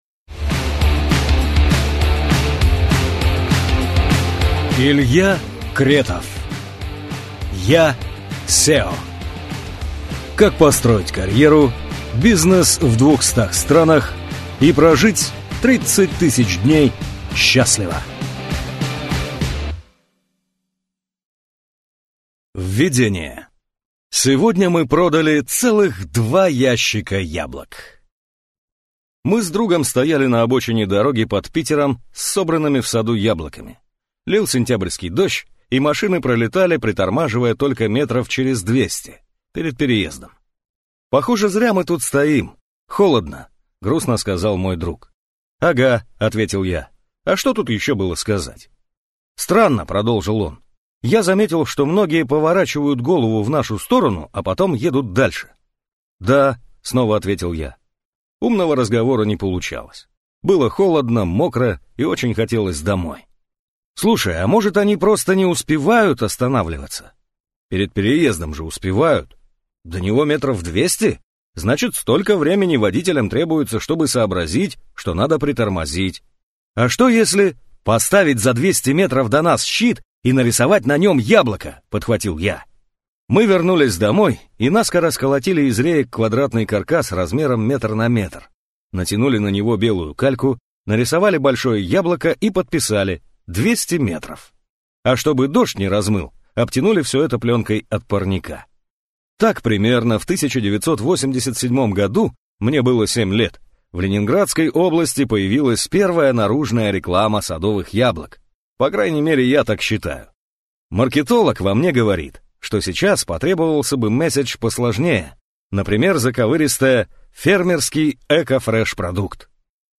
Аудиокнига Я – CEO. Как построить карьеру, бизнес в 200 странах и прожить 30 000 дней счастливо | Библиотека аудиокниг